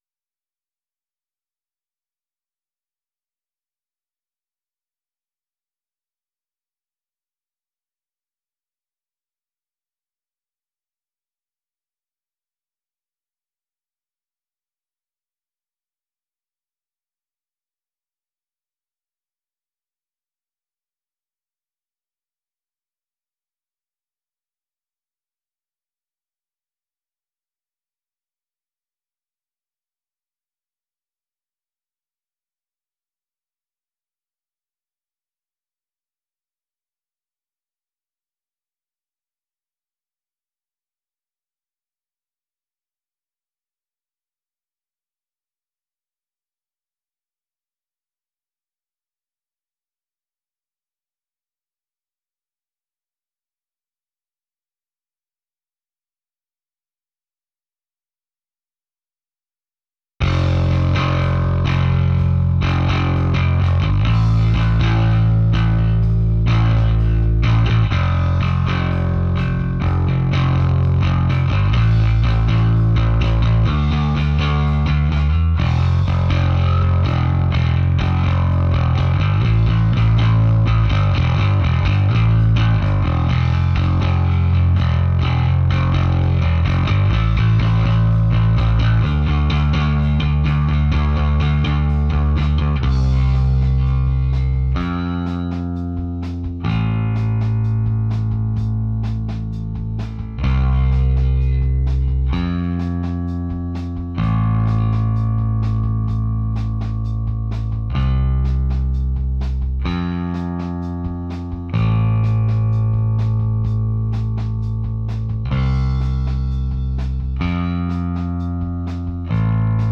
White BasMic.wav